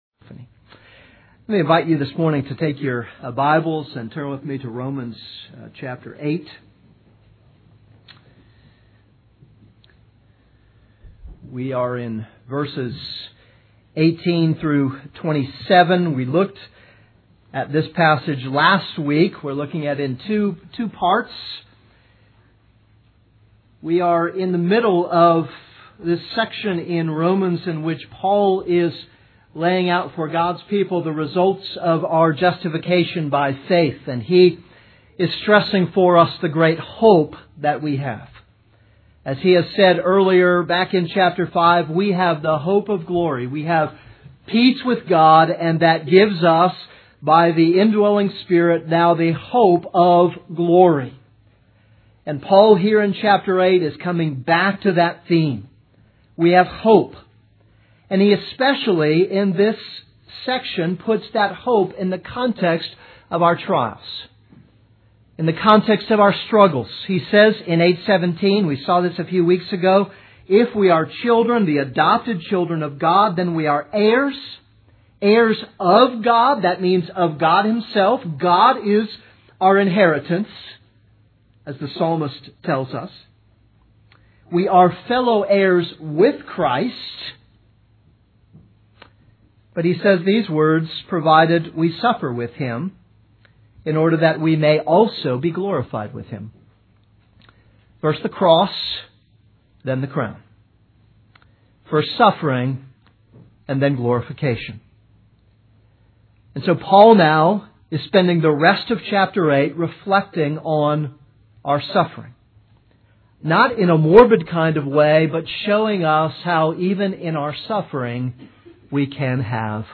This is a sermon on Romans 8:18-27.